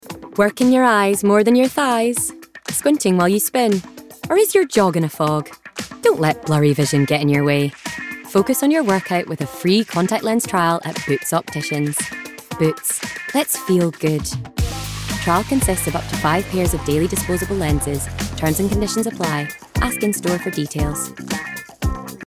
Voice Reel
Boots - Fun, Natural, Upbeat